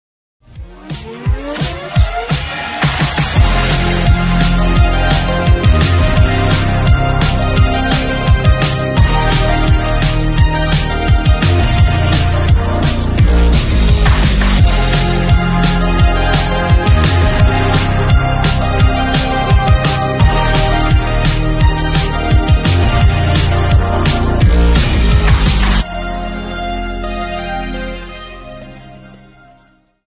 Category: Instrumental Ringtones